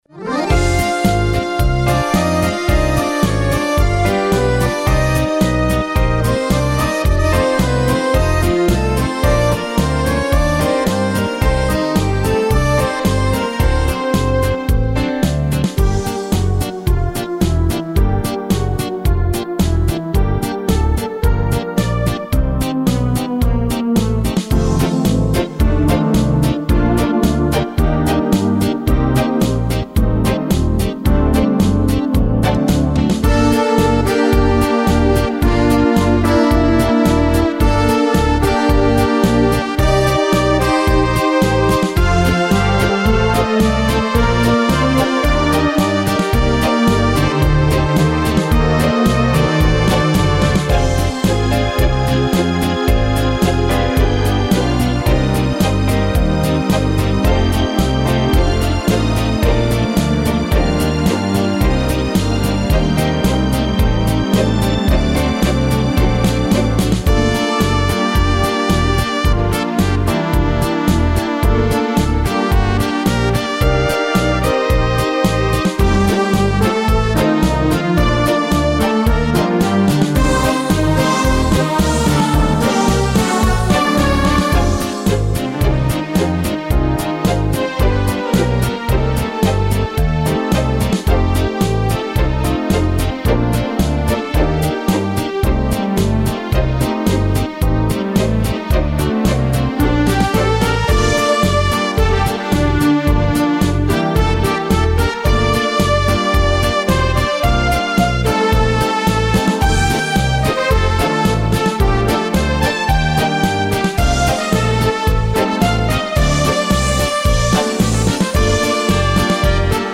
Качество:Студия